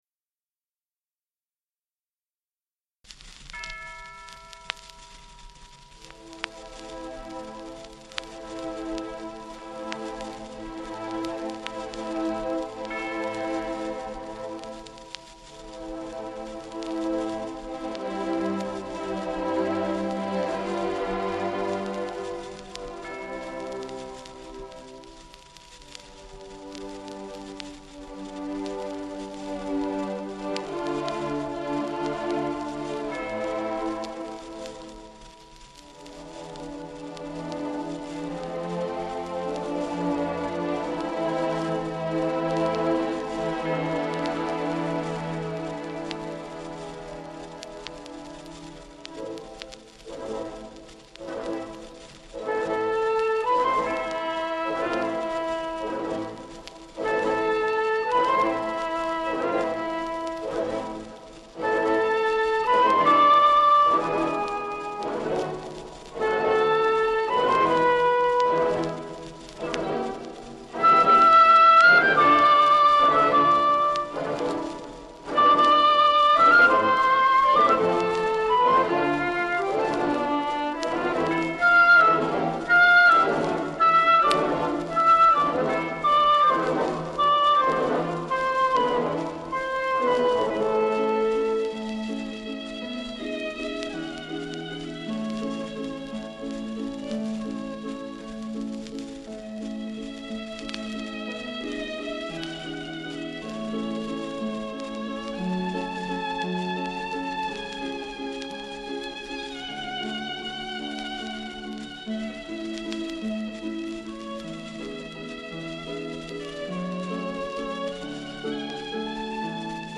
2. Ideal dynamic range plus clarity and brilliance.